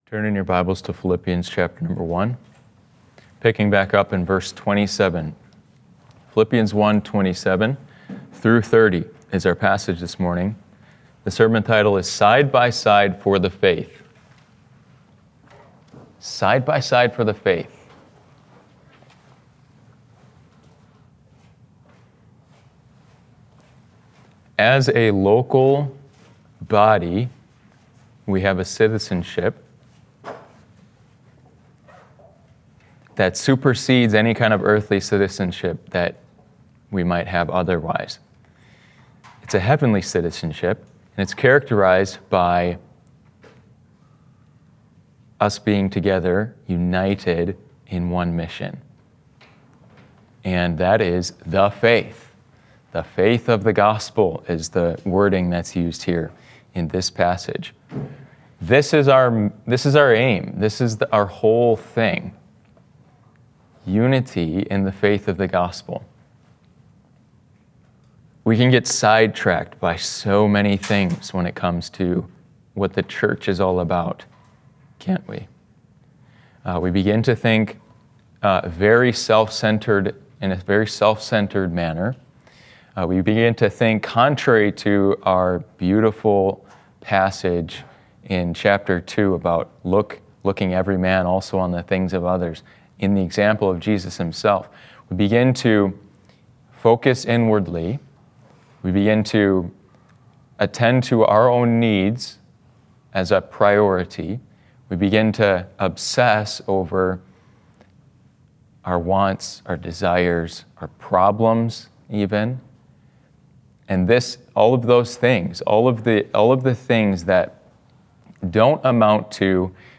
Side by Side for the Faith —Sunday AM Service— Passage: Philippians 1:27–30 Series